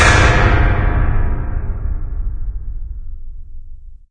Звук звонкий удар по металу.